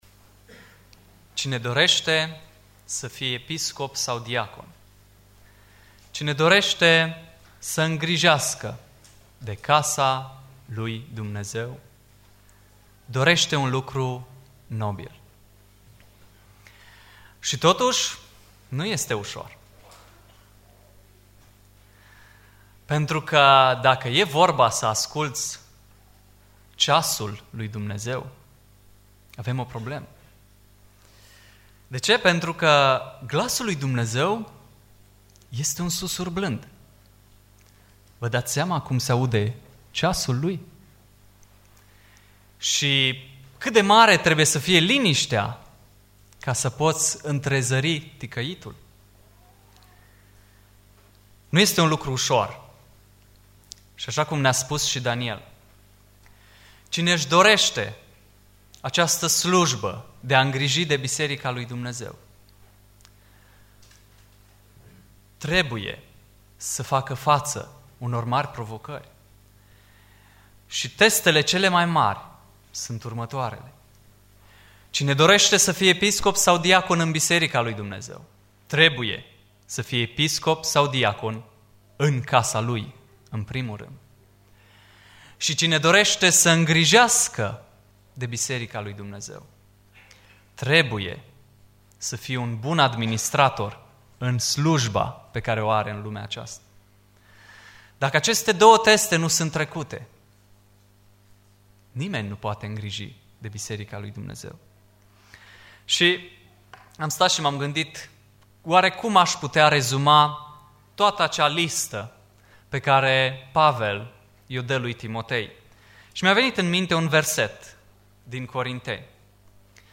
Predica Aplicatie 1 Timotei 3:1-15